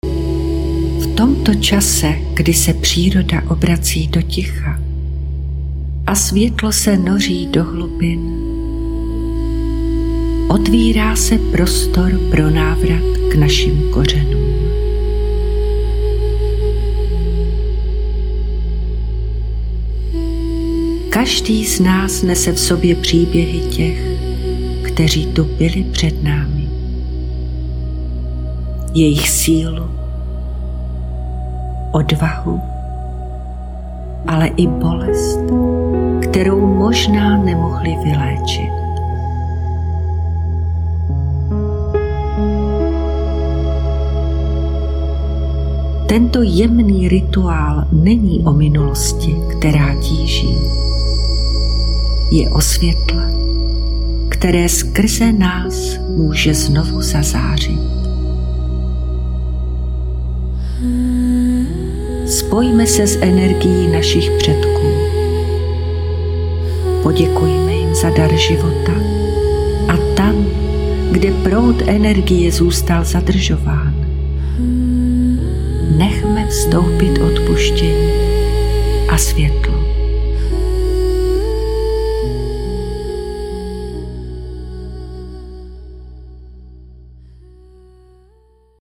Ukázka z meditace na očistu rodových linií: Celková délka 00:20:44